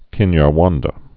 (kĭnyär-wändə)